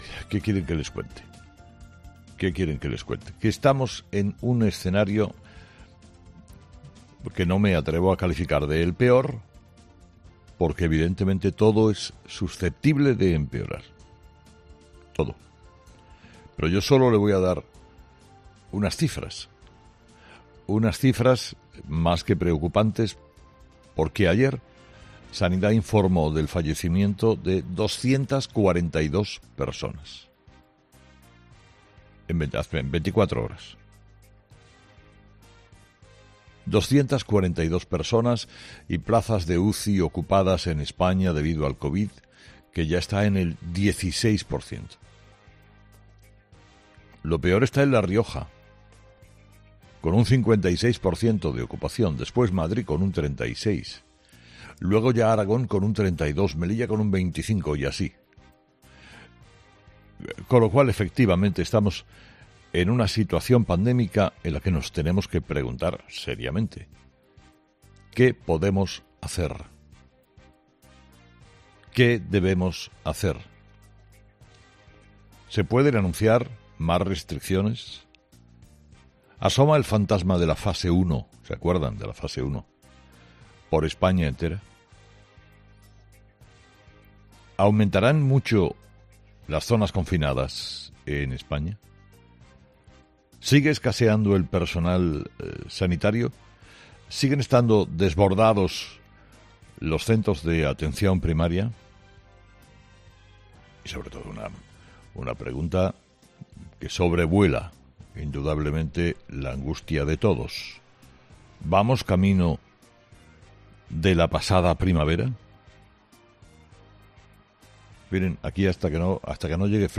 Carlos Herrera, director y presentador de 'Herrera en COPE', ha comenzado el programa de este miércoles analizando las claves de la pandemia en nuestro país, señalando que la situación, con el paso de los días, se está complicando más: "Estamos es un escenario en el que todo es susceptible de empeorar.